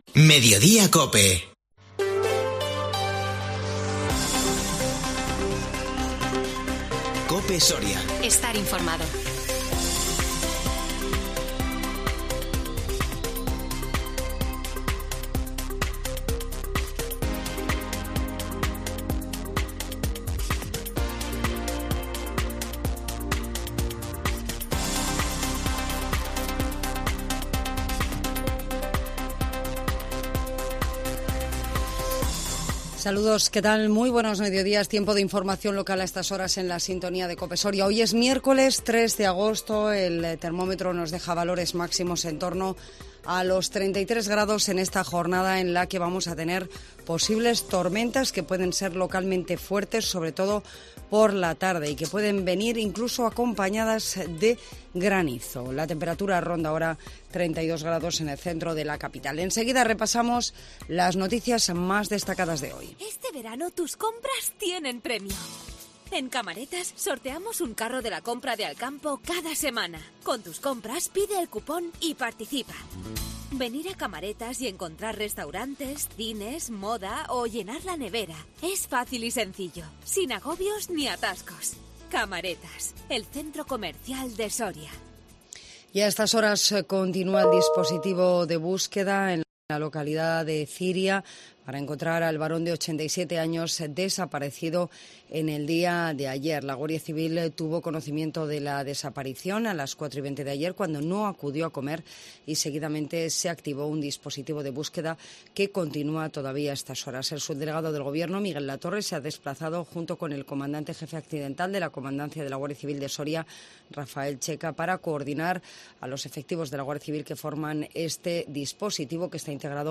INFORMATIVO MEDIODÍA COPE SORIA 3 AGOSTO 2022